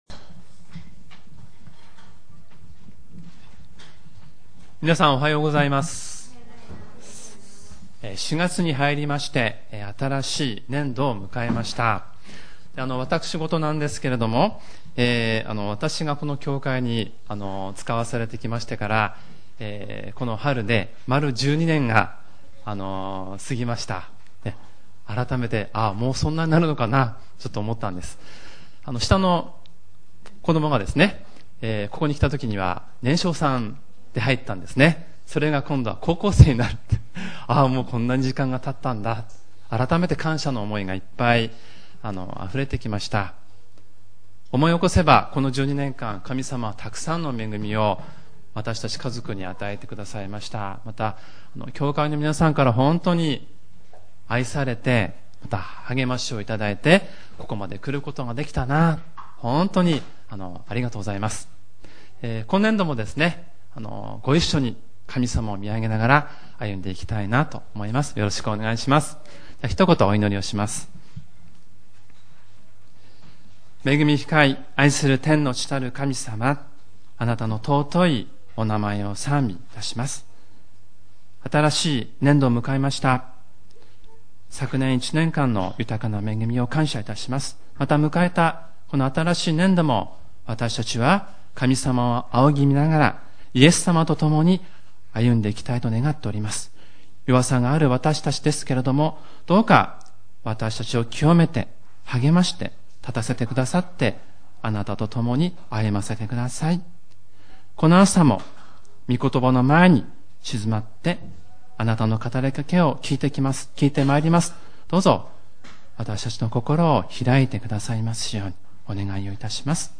主日礼拝メッセージ